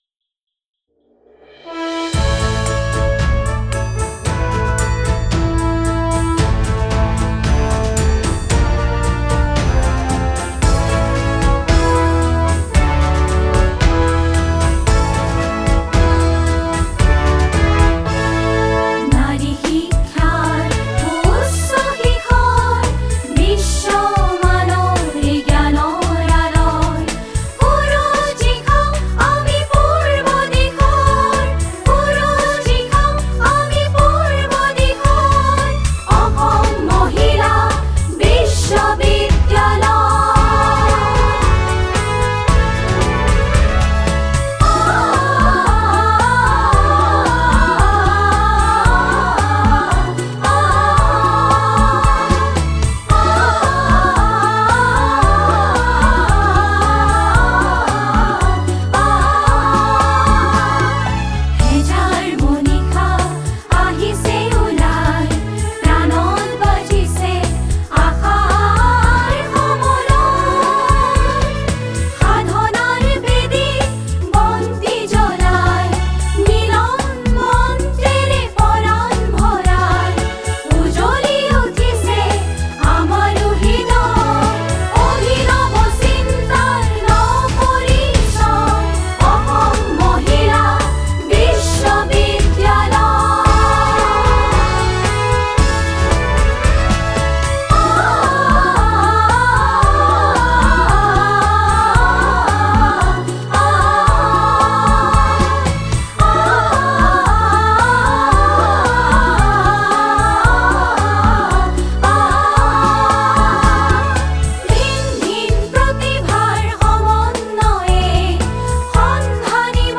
AWU | University Anthem
awu_anthem.mp3